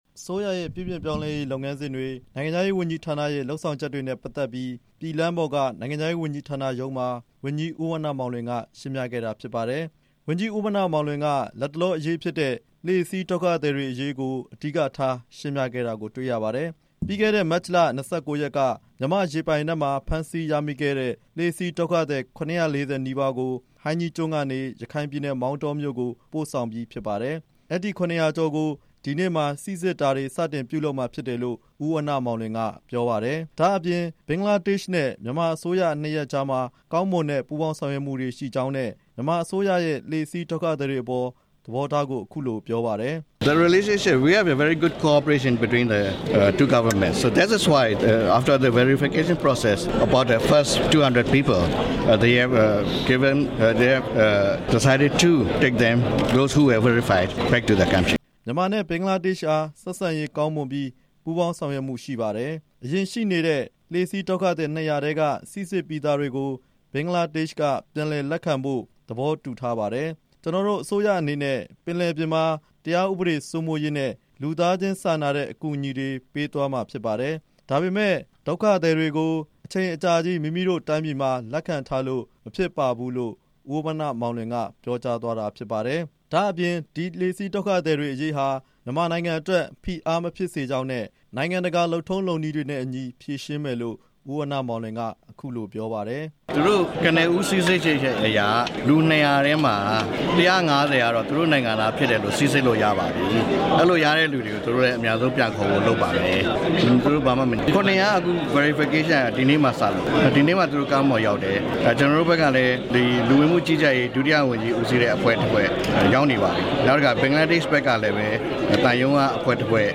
သတင်းစာရှင်းလင်းပွဲ တင်ပြချက်